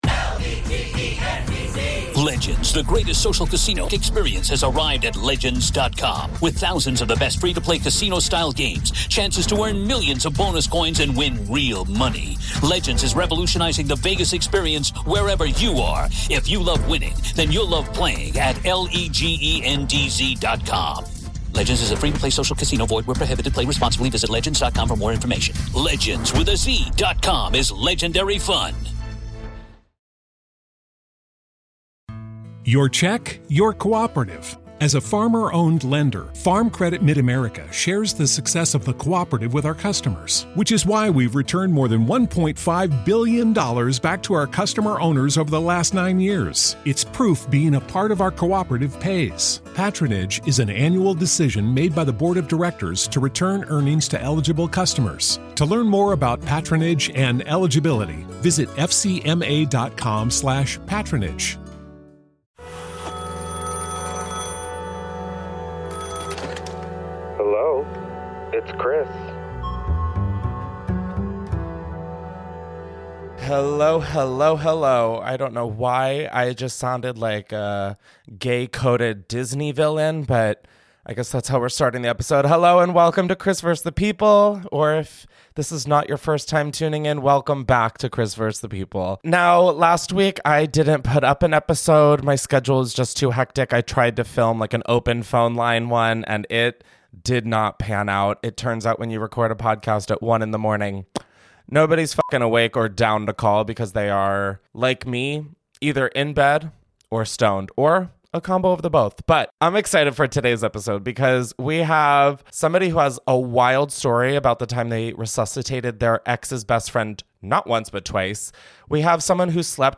First, we hear from a caller who had to resuscitate their ex’s best friend not once, but twice…only to later discover something disturbing about their ex.